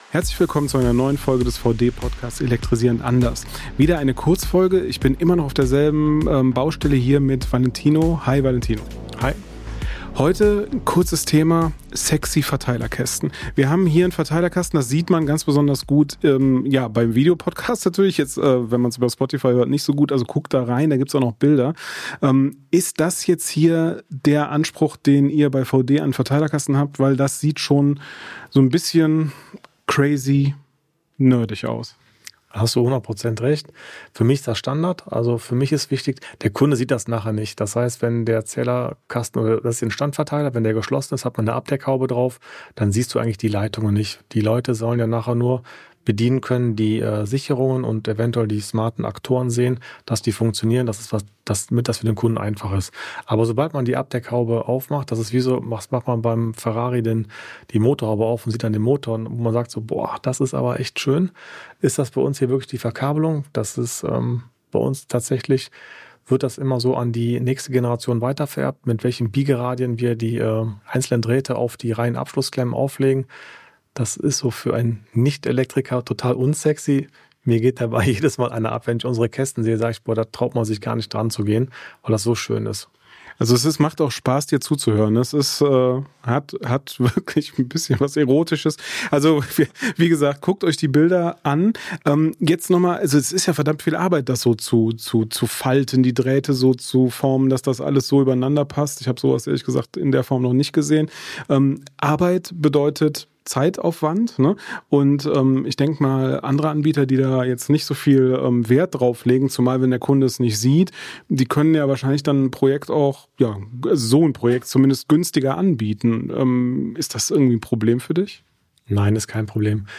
immer noch auf der Baustelle in Bonn
Moderator